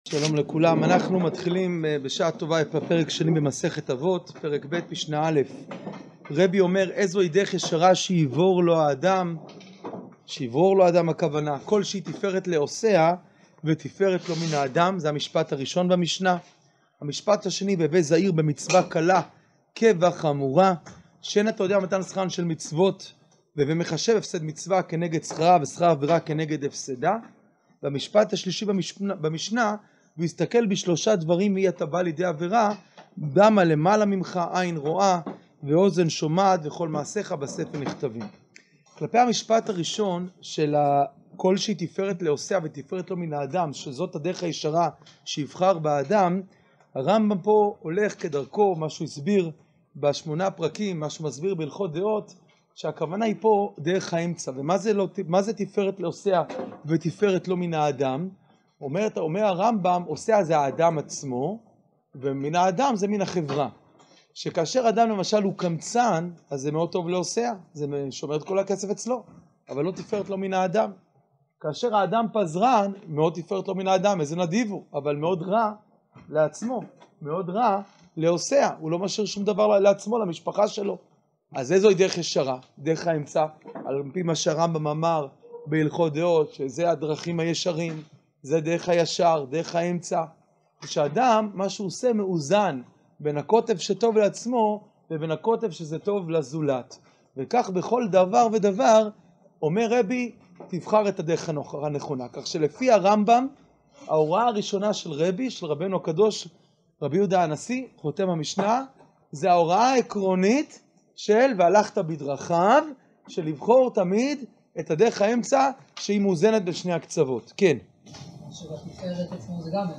שיעור פרק ב משנה א